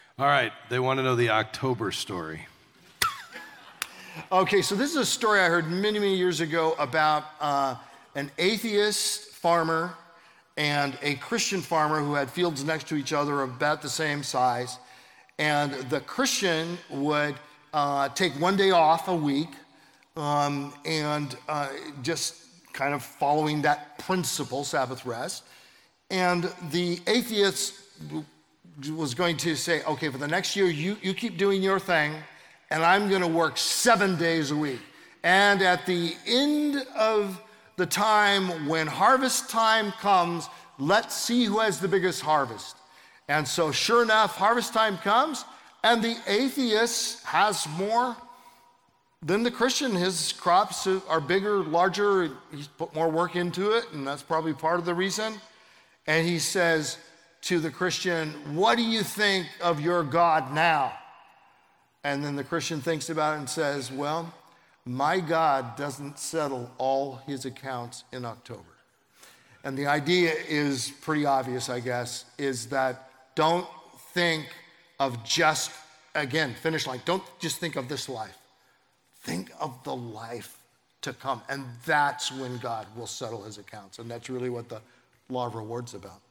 In this clip from a Q&A at the Kingdom Advisors Conference